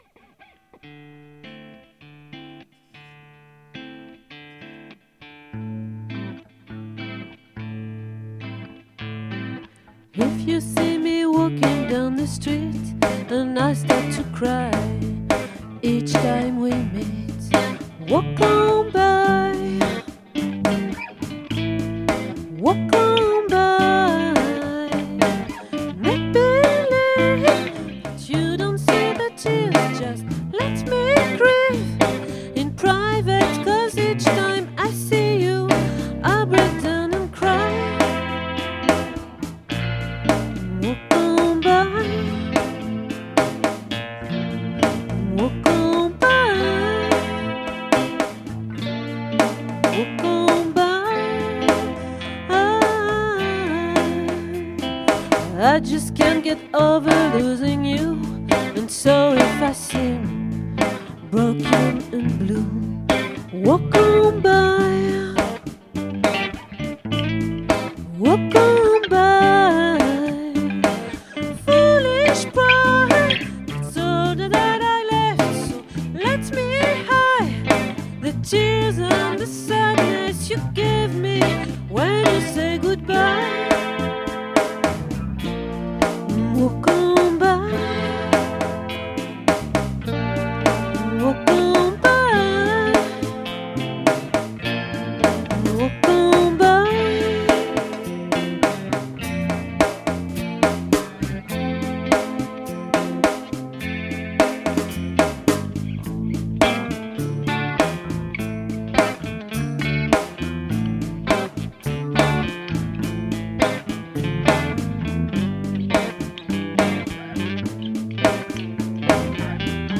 🏠 Accueil Repetitions Records_2022_02_02